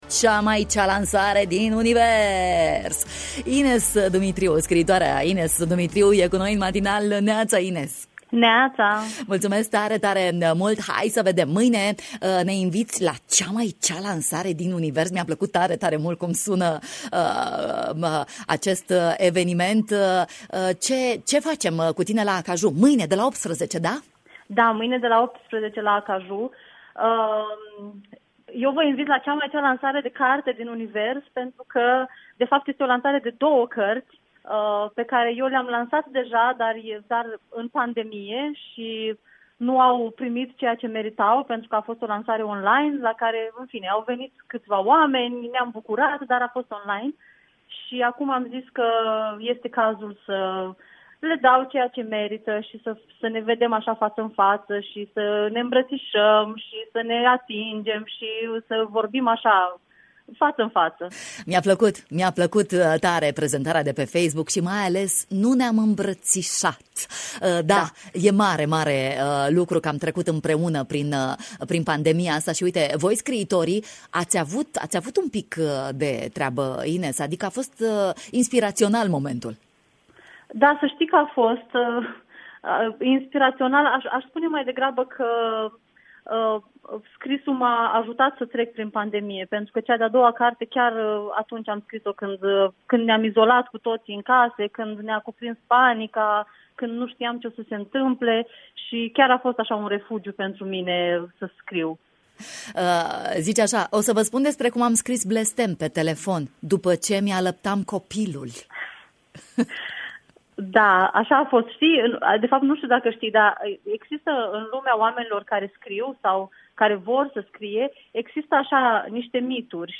în direct în matinalul de la Radio România Iași: